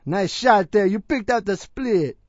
gutterball-3/Gutterball 3/Commentators/Louie/l_youpickedupthesplit.wav at 608509ccbb5e37c140252d40dfd8be281a70f917
l_youpickedupthesplit.wav